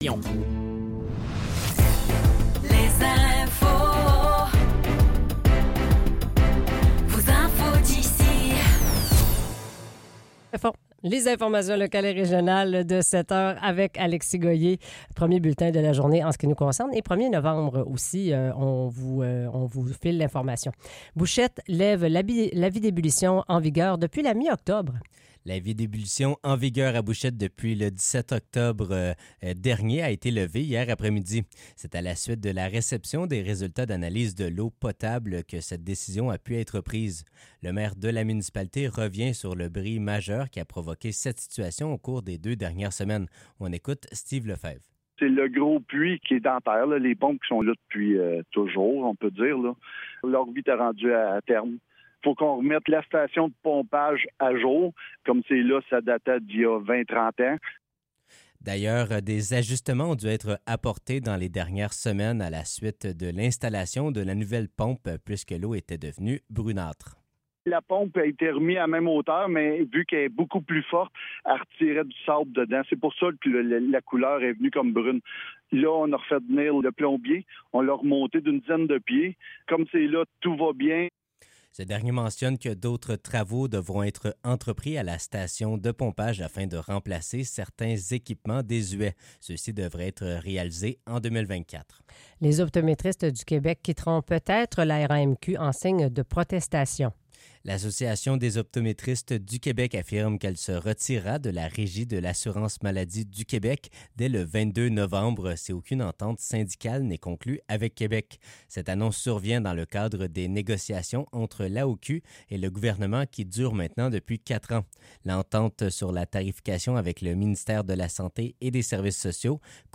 Nouvelles locales - 1er novembre 2024 - 7 h